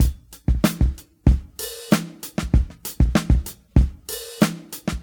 • 95 Bpm Seventies Fusion Drum Loop B Key.wav
Free breakbeat - kick tuned to the B note. Loudest frequency: 1208Hz
95-bpm-seventies-fusion-drum-loop-b-key-NSv.wav